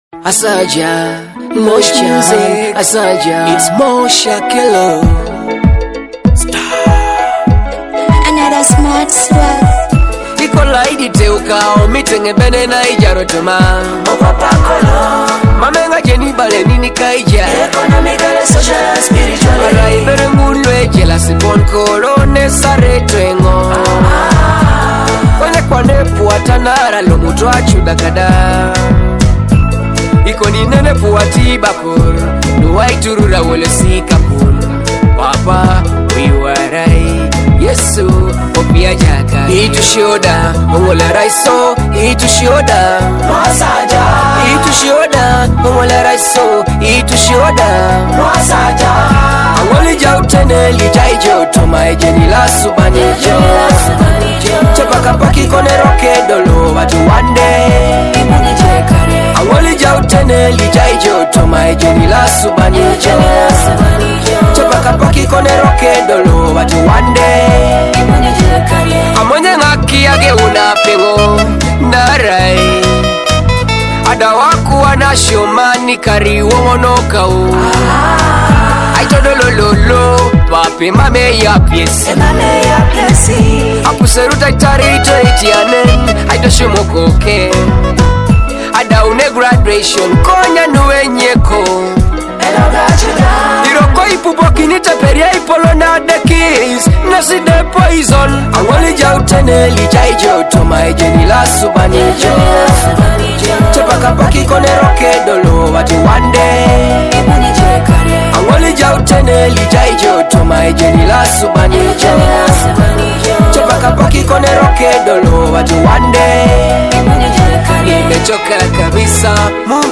powerful and heartfelt track